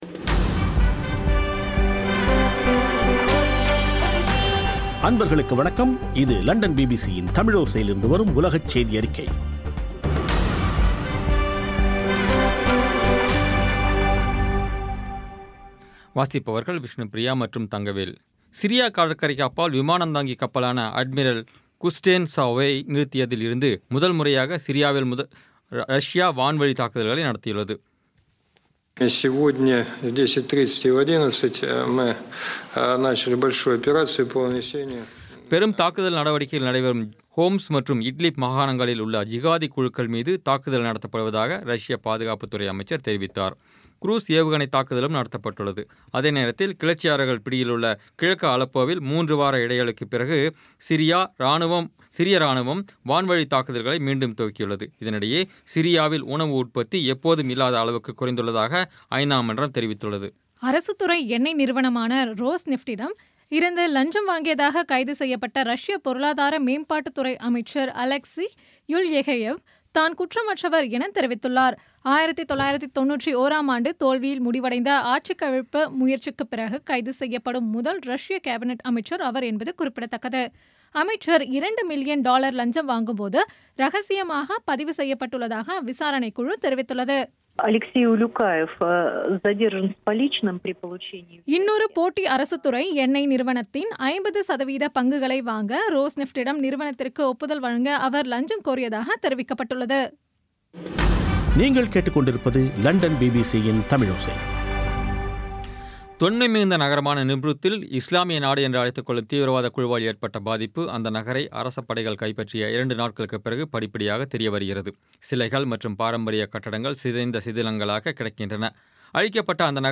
பி பி சி தமிழோசை செய்தியறிக்கை (15/11/2016)